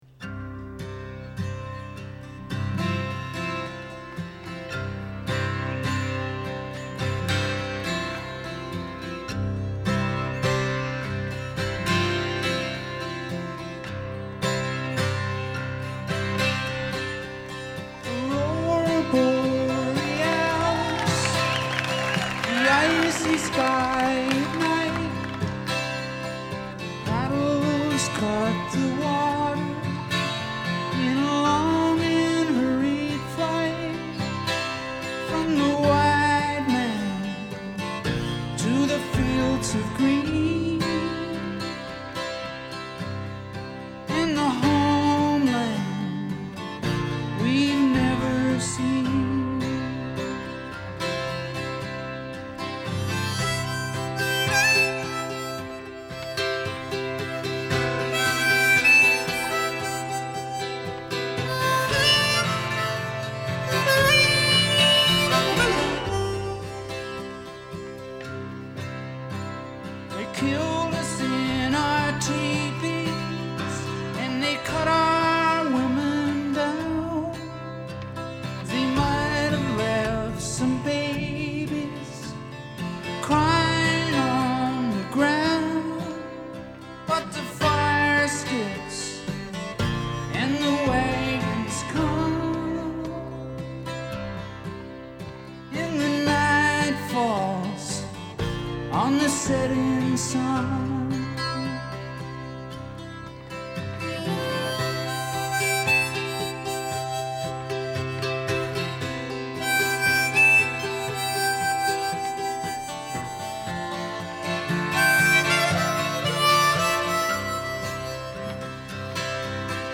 I love the 12 string version on the latter release.